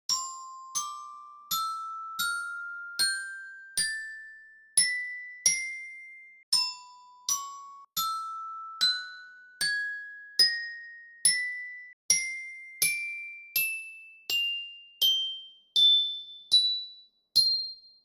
Hand-knells_Sound.mp3